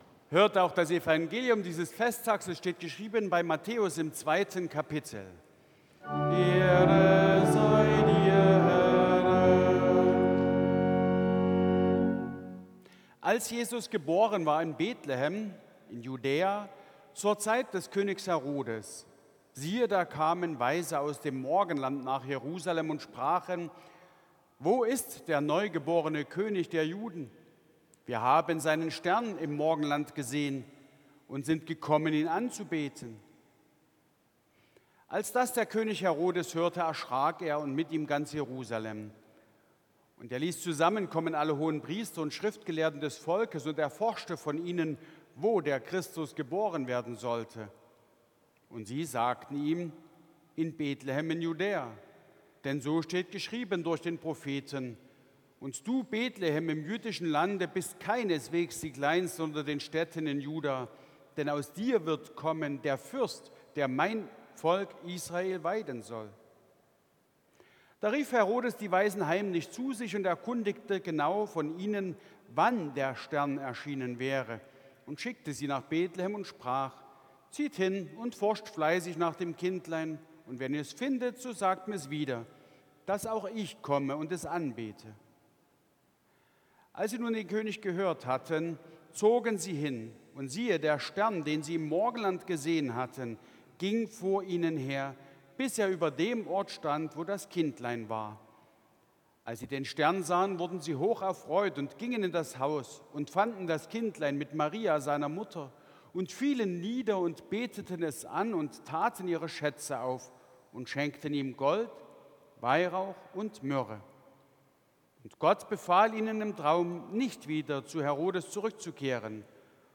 Evangeliumslesung aus Matthäus 2,1-12 Ev.-Luth.
Audiomitschnitt unseres Gottesdienstes zum Epipaniasfest 2026.